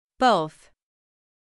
/θ/は息だけで、/ð/は喉を震わせるのがポイントです。
both [boʊθ]